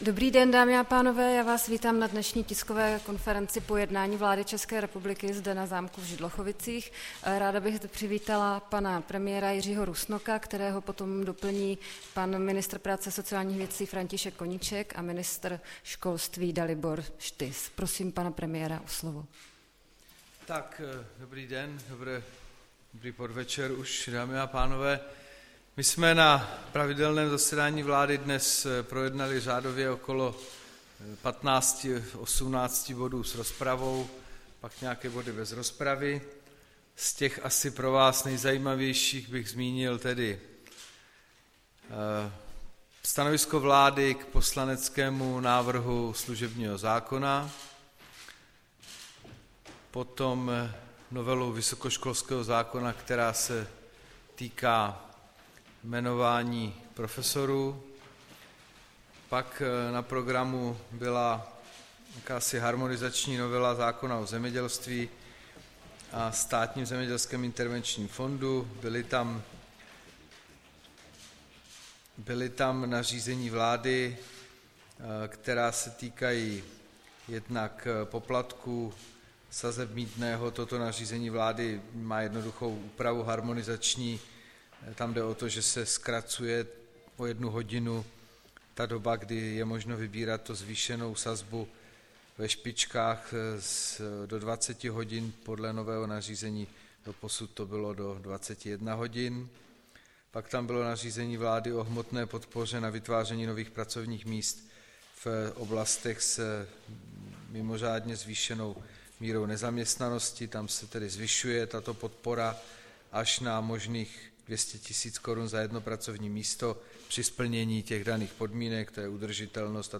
Tisková konference po jednání vlády, 8. ledna 2014